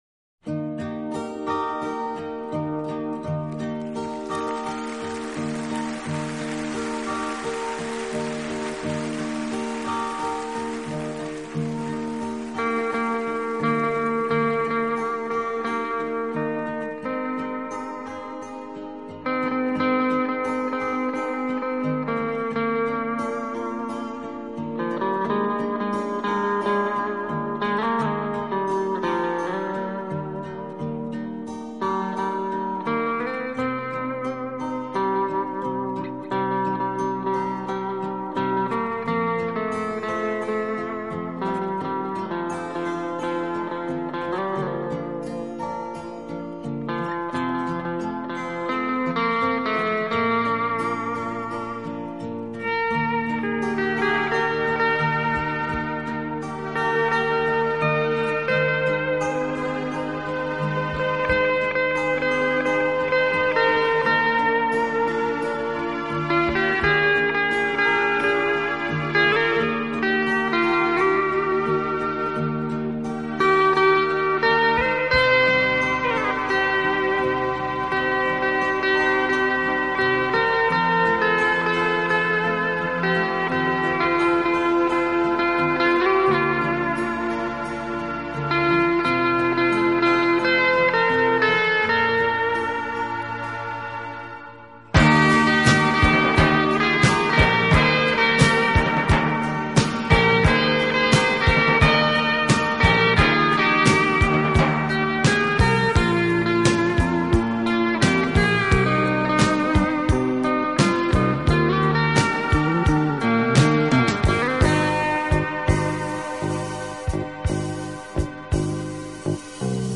【轻音乐】